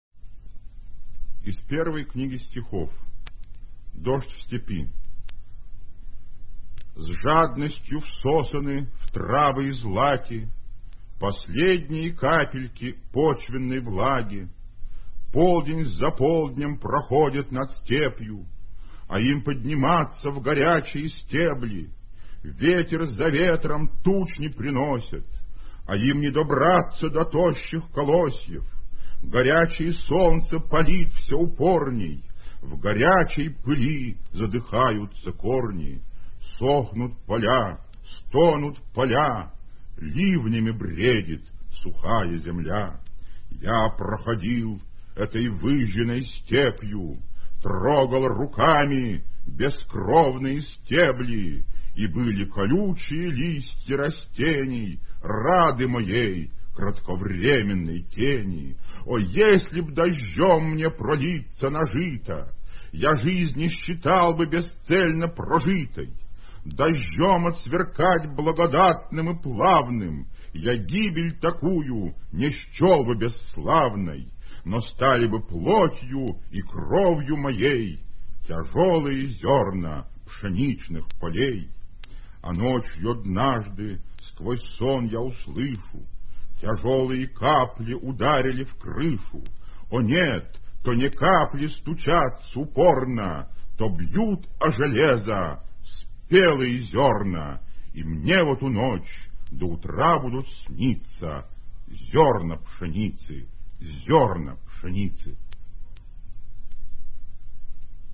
1. «Владимир Солоухин – Дождь в степи (читает автор)» /